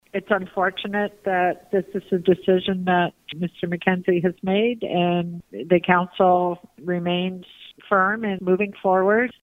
Here’s Deputy Mayor Lori Hoddinott.
Deputy Mayor Lori Hoddinott speaks directly to Township residents who may be concerned.